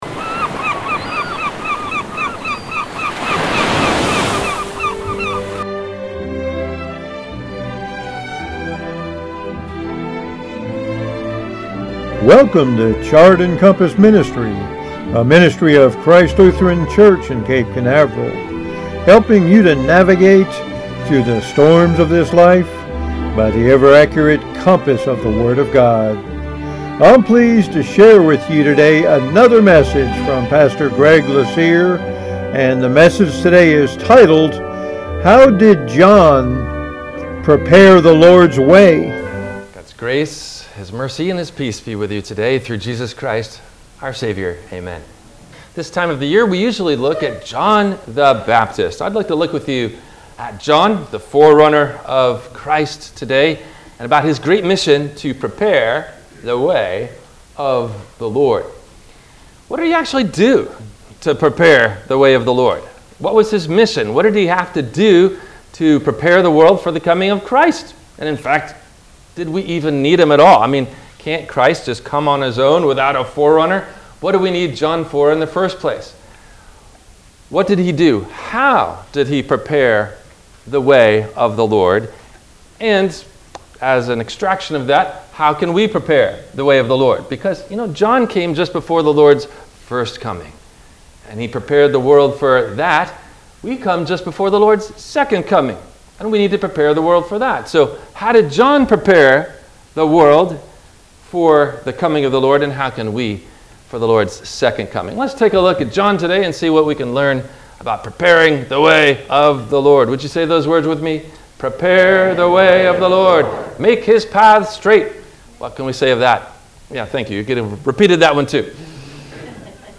No Questions asked before the Sermon message:
WMIE Radio – Christ Lutheran Church, Cape Canaveral on Mondays from 12:30 – 1:00